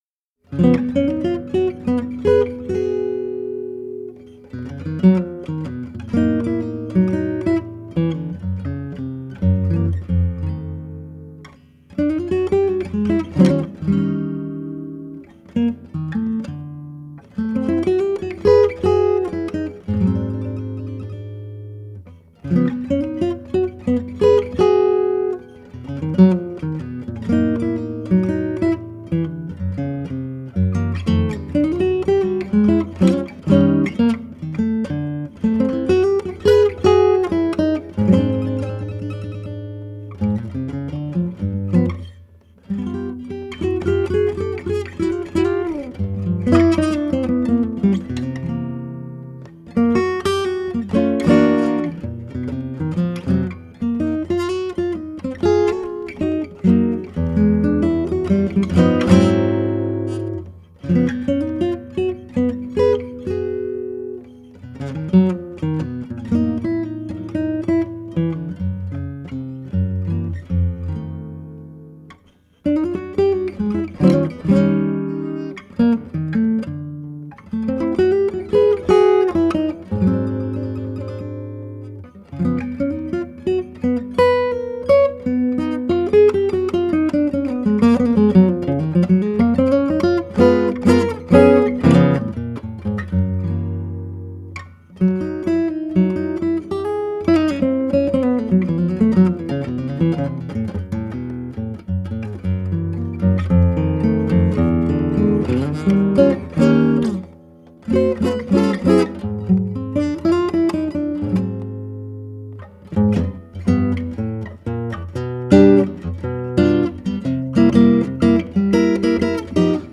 jazz
solo guitar